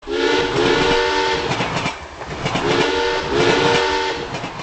Zug klingelton kostenlos
Kategorien: Soundeffekte
zug.mp3